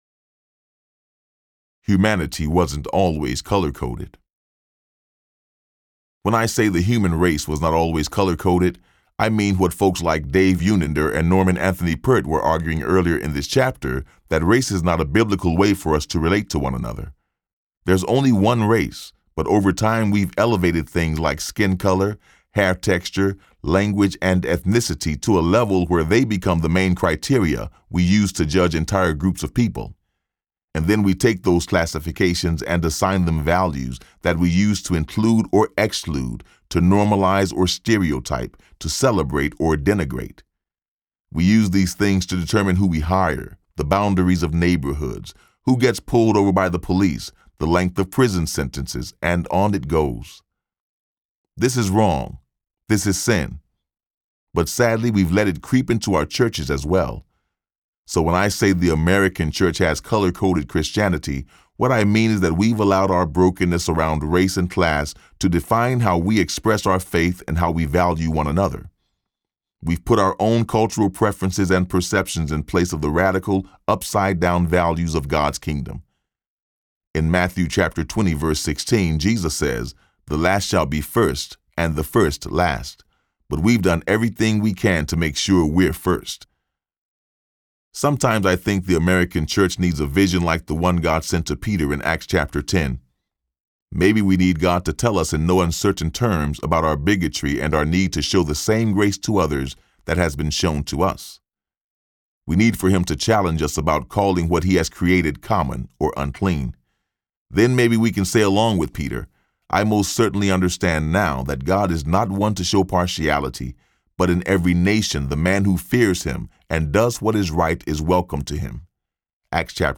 One Blood Audiobook
Narrator